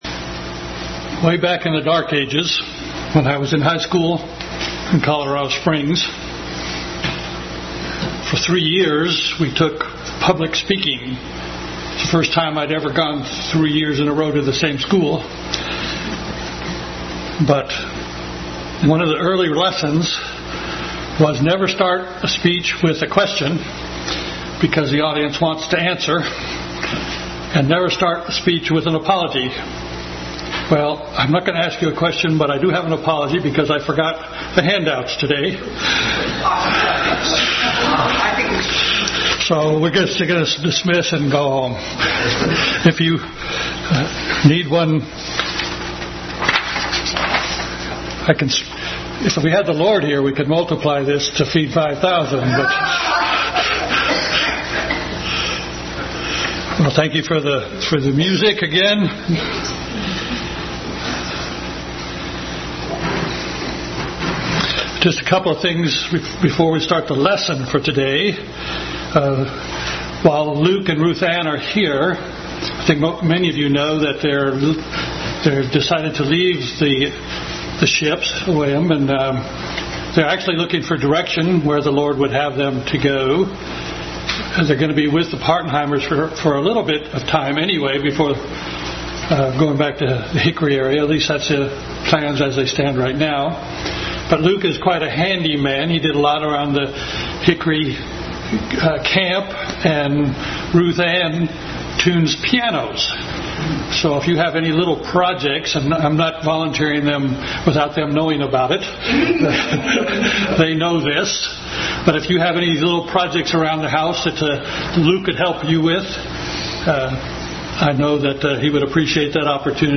Bible Text: 1 Corinthians 12 and various other Scriptures | Family Bible Hour session 4 on Spiritual Gifts.